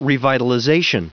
Prononciation du mot revitalization en anglais (fichier audio)
Prononciation du mot : revitalization